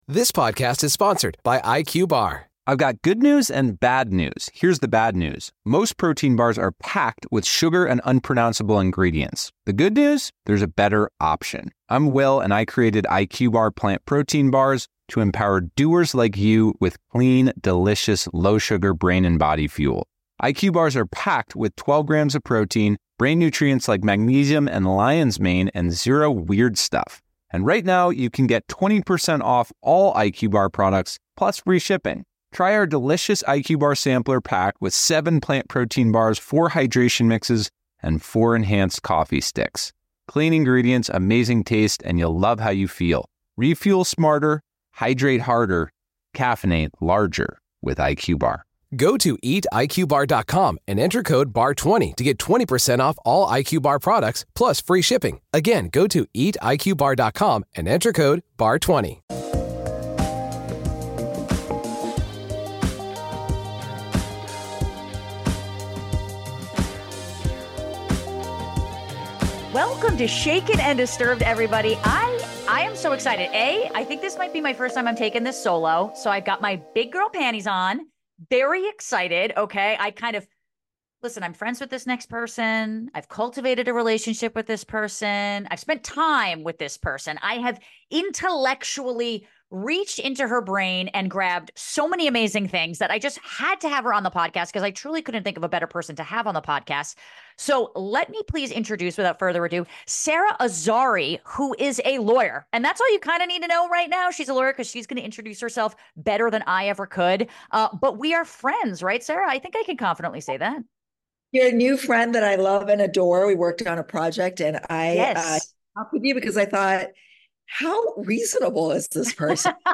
You don't want to miss the captivating conversation!